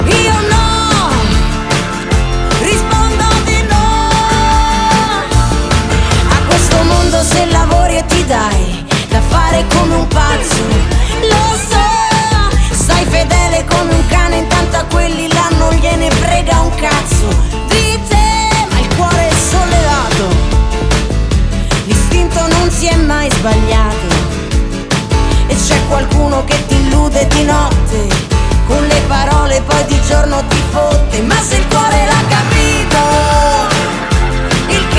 2016-02-12 Жанр: Поп музыка Длительность